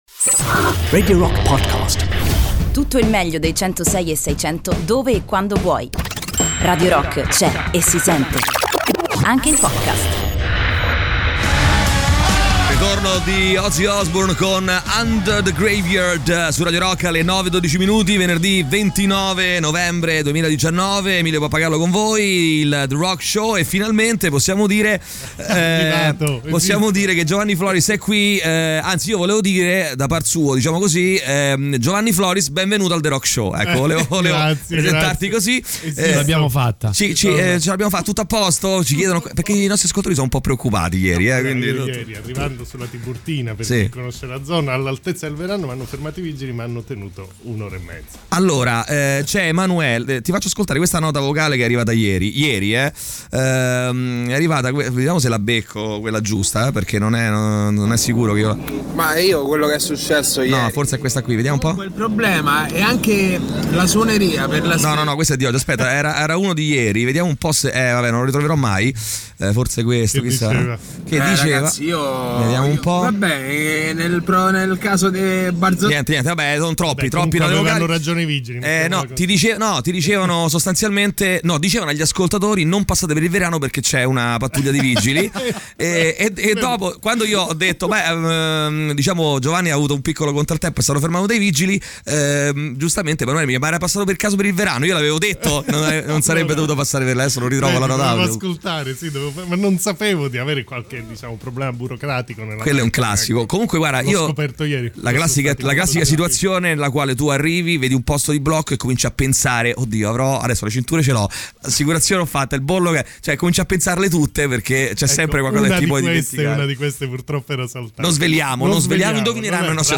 "Intervista": Giovanni Floris (29-11-19)